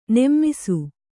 ♪ nemmisu